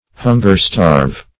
Search Result for " hunger-starve" : The Collaborative International Dictionary of English v.0.48: Hunger-starve \Hun"ger-starve`\, v. t. To starve with hunger; to famish.